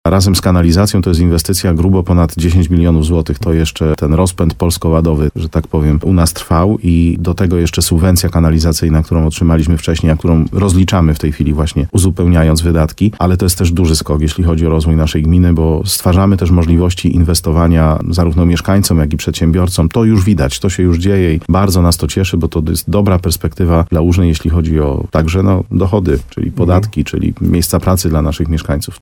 – To jest właśnie rozwój – mówił w programie Słowo za Słowo w radiu RDN Nowy Sącz wójt gminy Łużna Mariusz Tarsa.